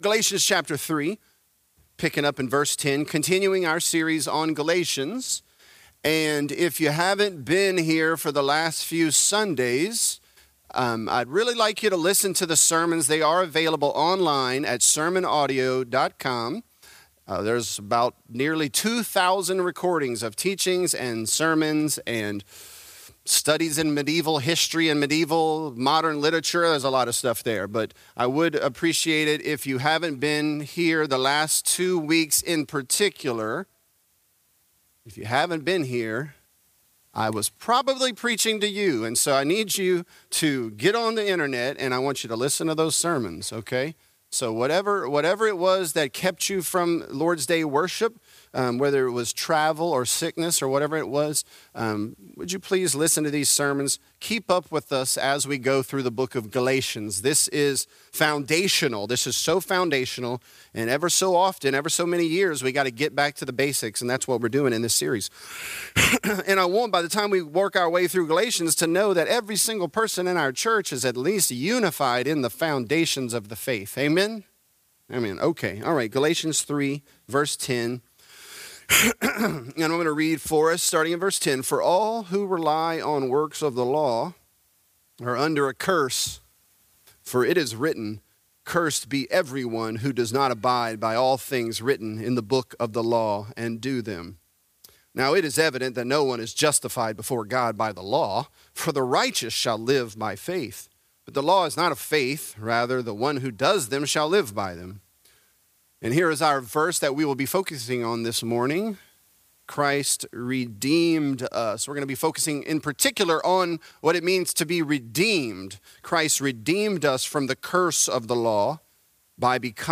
Galatians: Christ Redeemed Us | Lafayette - Sermon (Galatians 3)